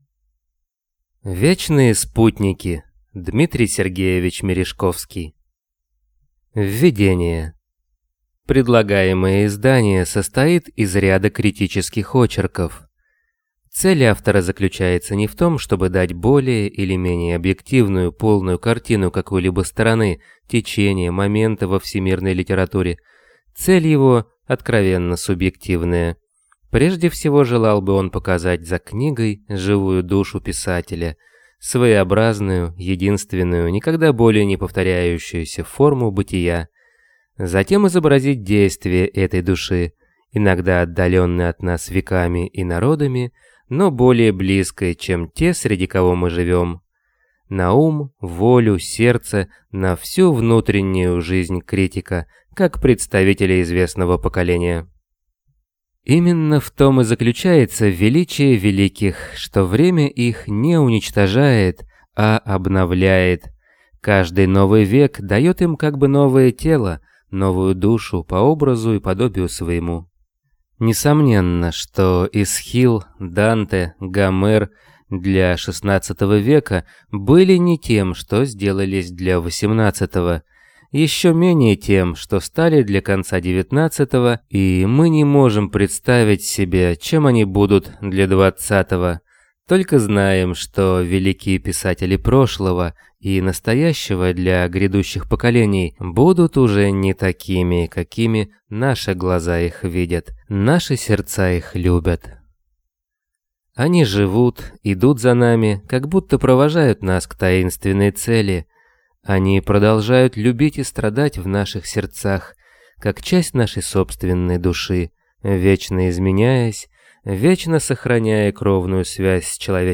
Аудиокнига Вечные спутники | Библиотека аудиокниг